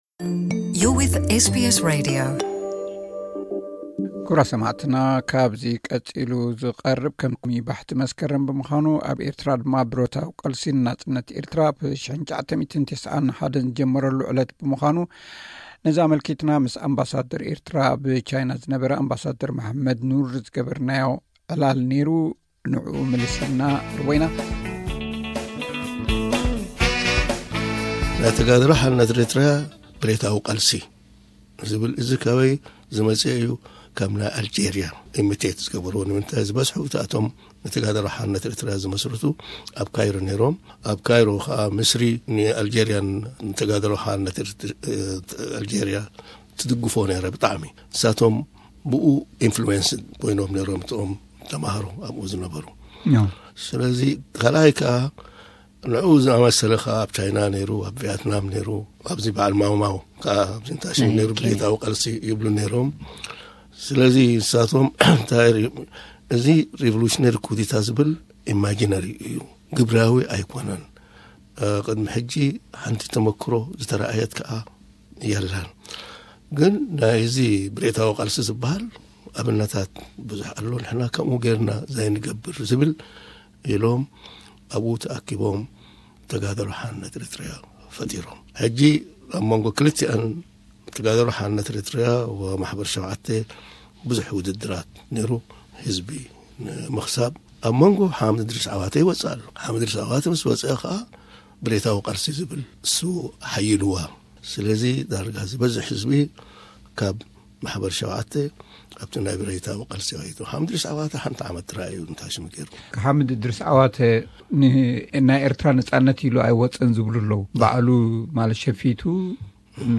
ባሕቲ መስከረምን ሓምድ እድሪስ ዓዋተን፡ ዕላል ምስ ኣምባሳደር መሓመድ ኑር